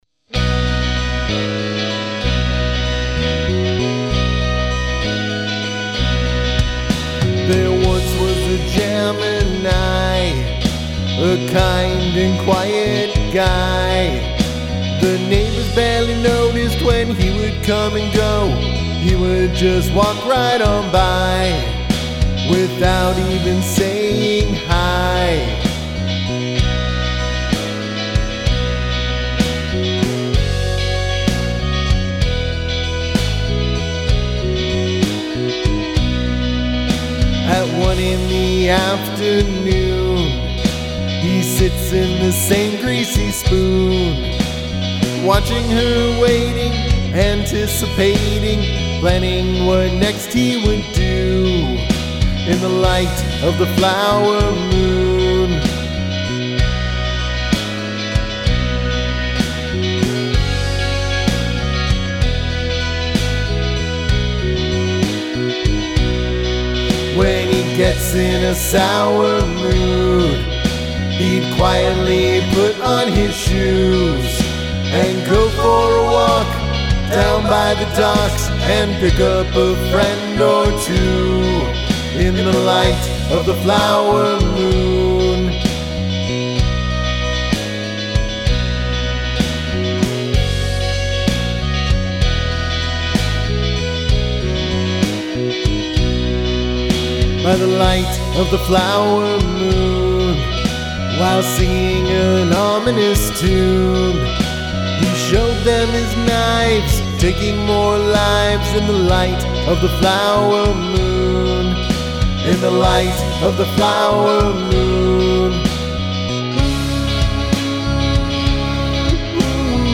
Narrative song about a murder
Capo 4th fret